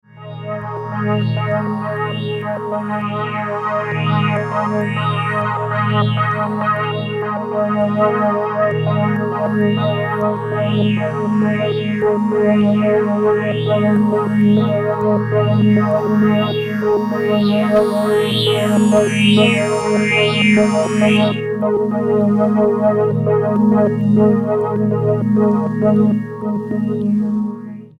raw and deep house tracks drenched in melancholy.